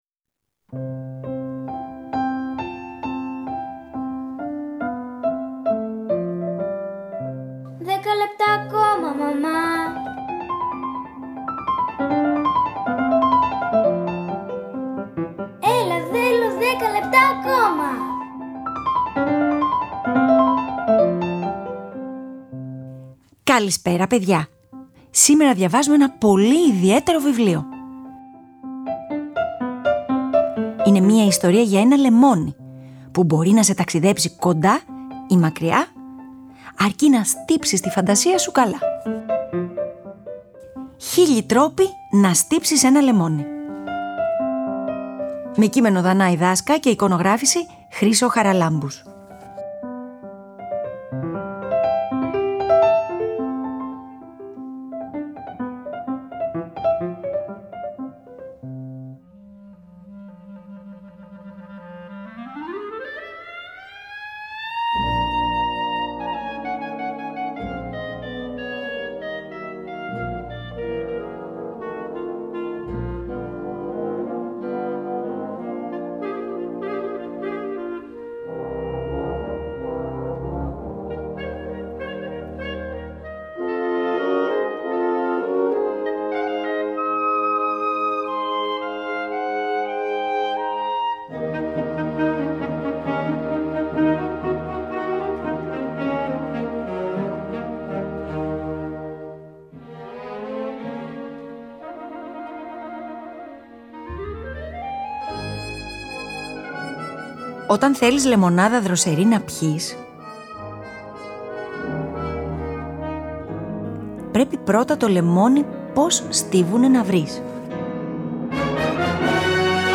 George Gershwin, Rhapsody in blue